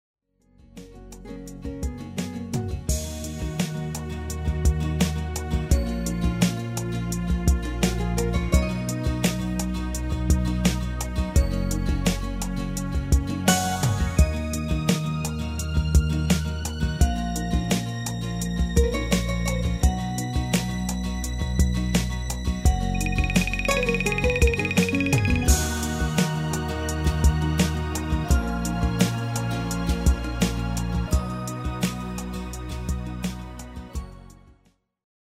GM-Only MIDI File Euro 8.50
Demo's zijn eigen opnames van onze digitale arrangementen.